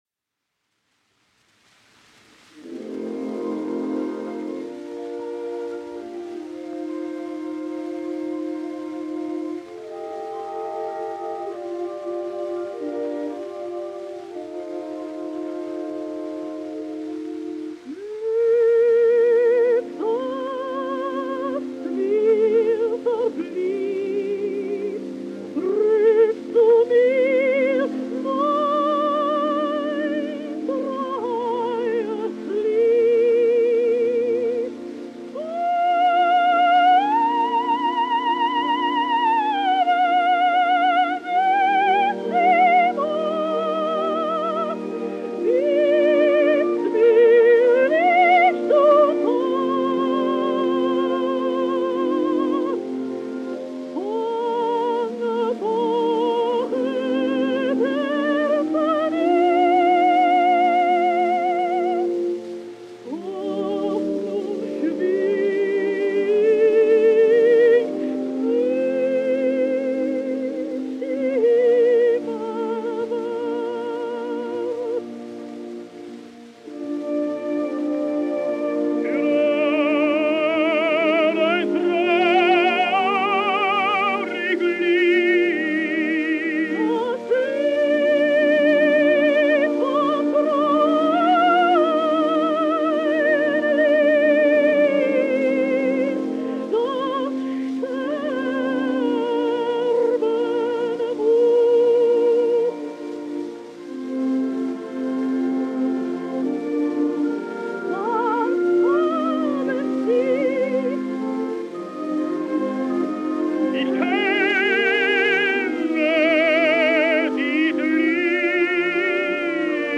Acoustic 12″ recordings made from 1924-1926.
’24 Berlin State Opera Orchestra; c. George Szell
062 DIE TOTE STADT: Glück, das mir verblieb; (Korngold); this was so successful that it was re-released with an electrically recorded orchestra dubbed over the same acoustic recording; with Richard Tauber;  062 Glück, das mir Verblieb (electric orchestra dub);